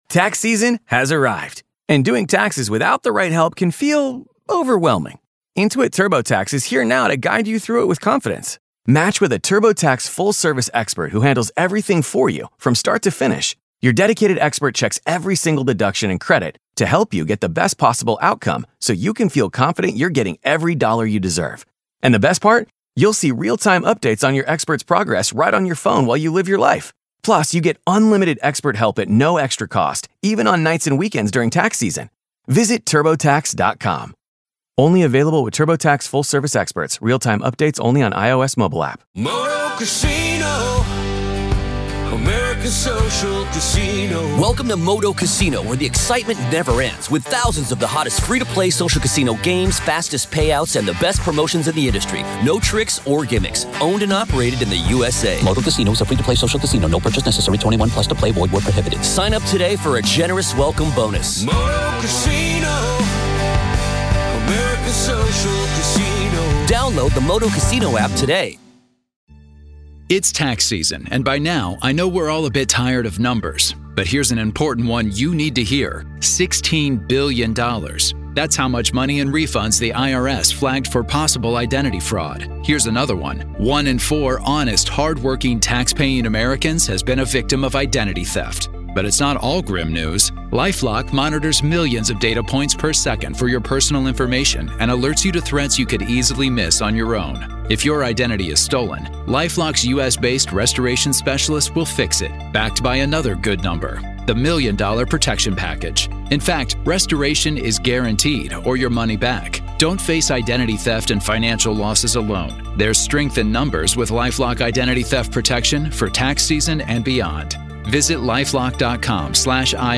What they uncovered suggested something far beyond a simple haunting. This conversation explores the progression from subtle disturbances to malicious oppression, the process of identifying potentially demonic forces, and the difficult steps taken to cleanse the home.